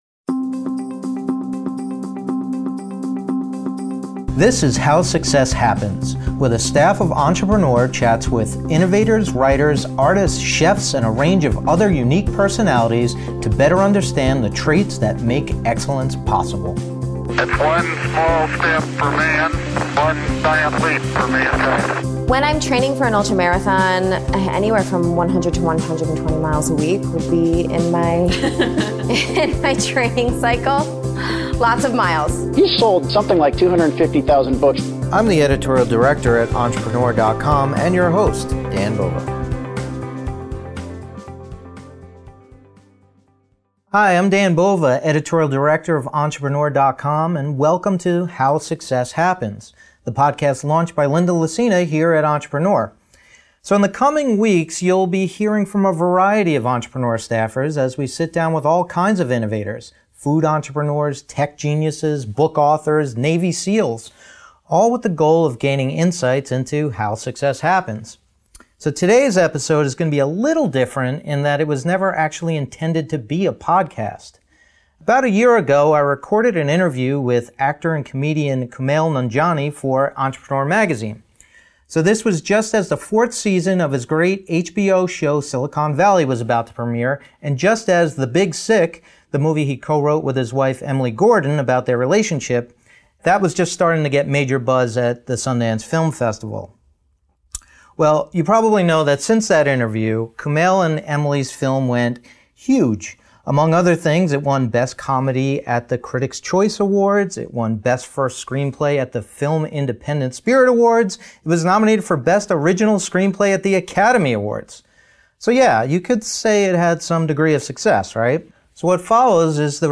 In this special episode, we listen in to the hilarious interview Kumail Nanjiani did for the May 2017 issue of Entrepreneur magazine. Learn about how he got his start in tech, how he got the courage to make the leap into comedy and how he and his wife, Emily V. Gordon, figured out how to work together without driving each other nuts.